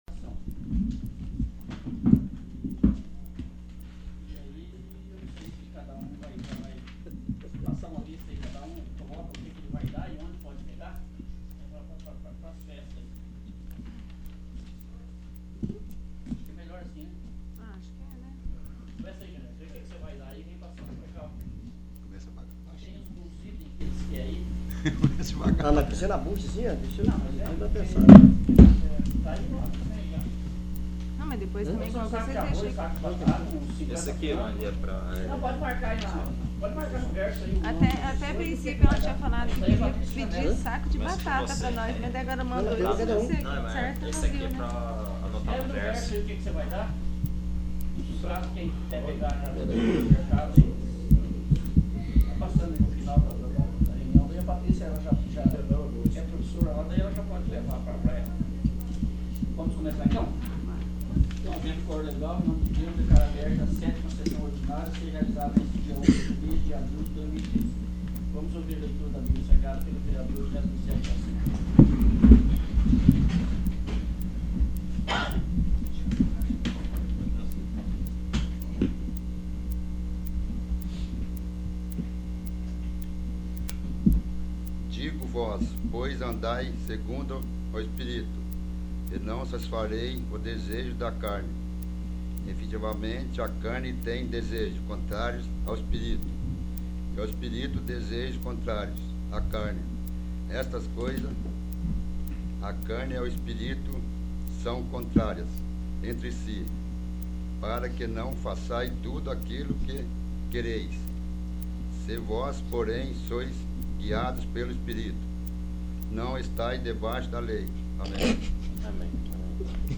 7º. Sessão Ordinária 01/04/2013
7º. Sessão Ordinária